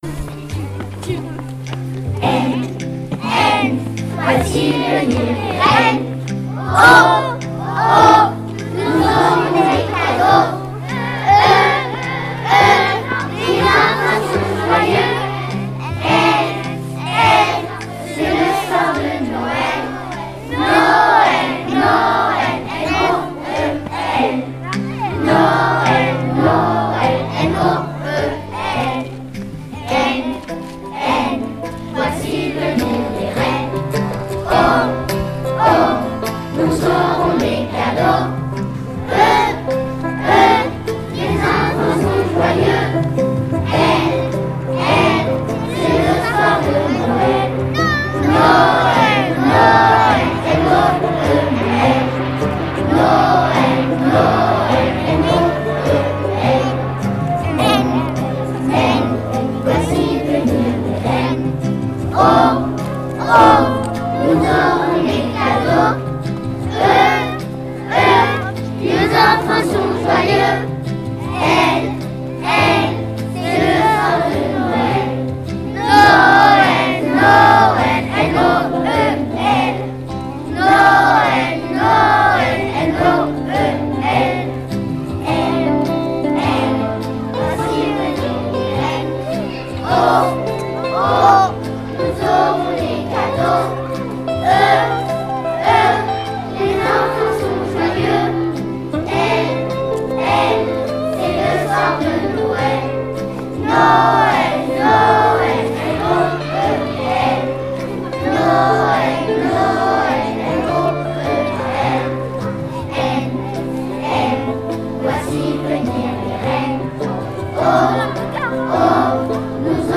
Mini-concert et visite du Père Noël
Les classes de CP et de CE1 ont réalisé un mini-concert pour fêter les vacances à venir.